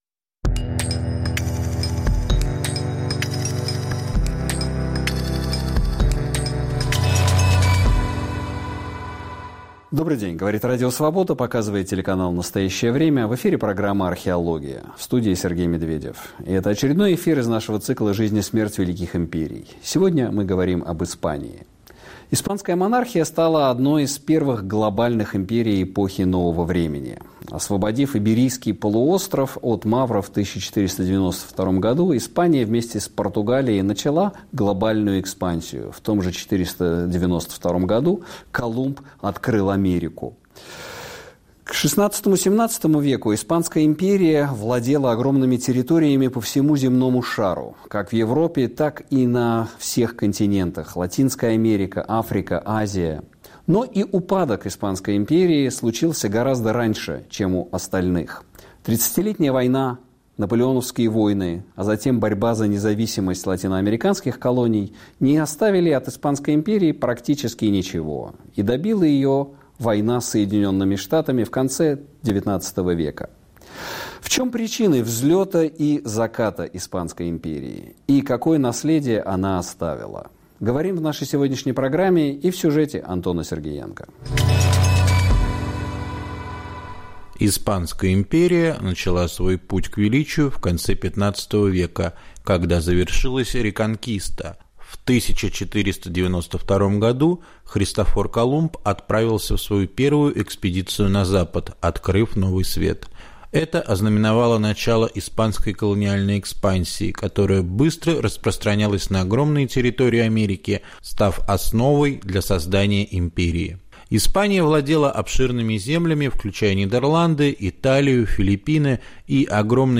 Повтор эфира от 28 августа 2024 года.